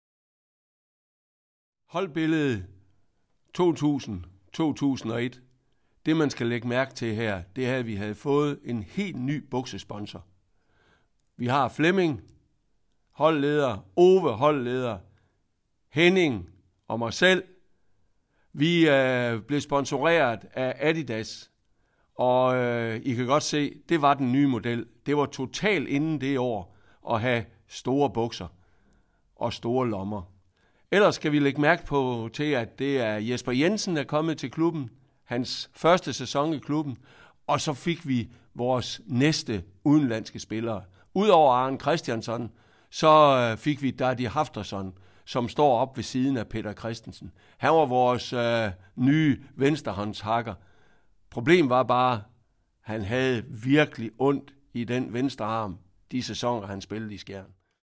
Klik på de enkelte fotos og hør Anders Dahl-Nielsens kommentarer om holdet/sæsonen